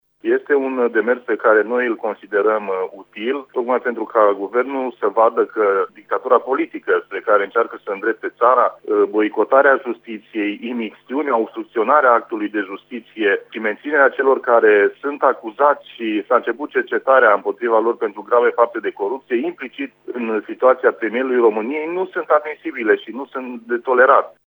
Senatorul de Mureș,  Marius Pașcan, unul dintre coinițiatorii acestei moțiuni, crede că numai astfel PSD va înțelege că legile trebuie respectate: